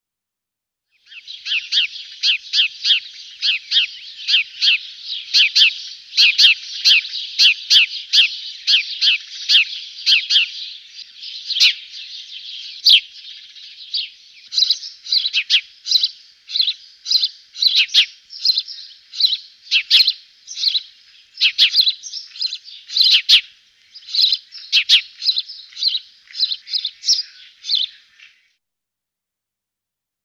Chant :
Moineau domestique
Le Moineau domestique chuchete, chuchote, pépie.
Le cri typique du moineau domestique est un vigoureux " chee-ep ", aussi un " chissick ", et un " chip " monotone.
Son chant est une série de " chirps " et de " chissicks " répétés.
83HouseSparrow.mp3